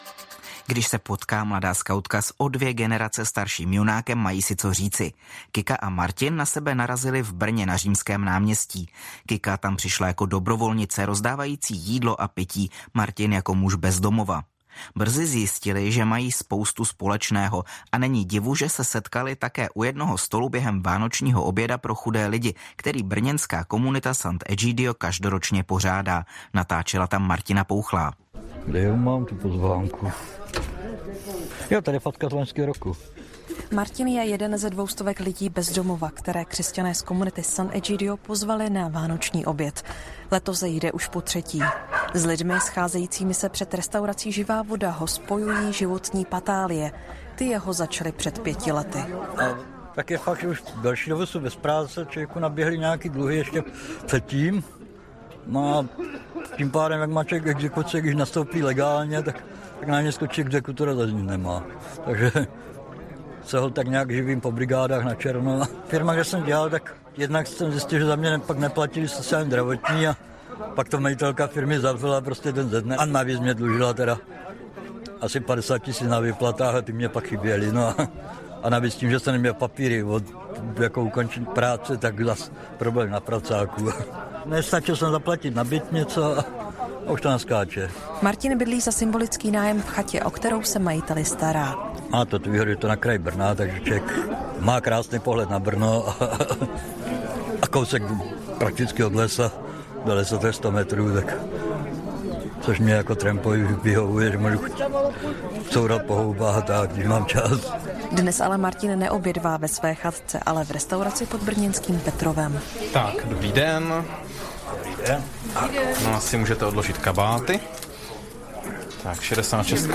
reportáž VO 2018 sestry